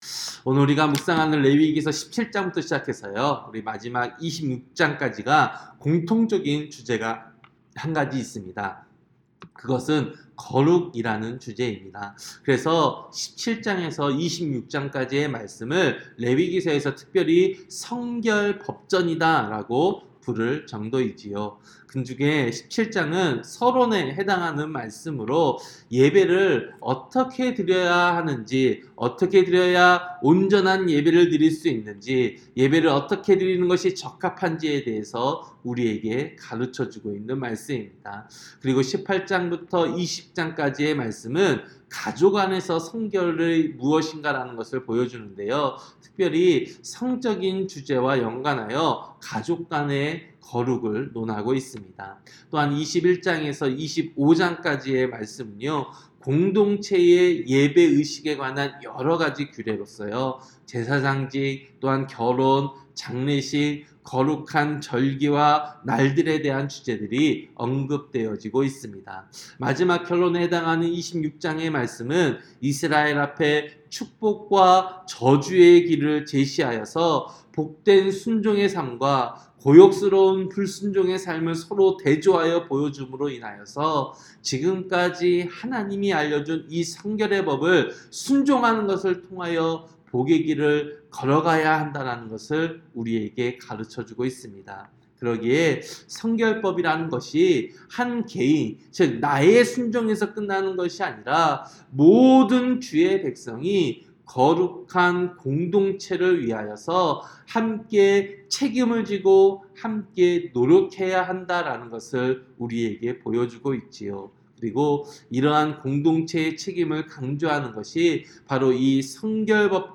새벽설교-레위기 17장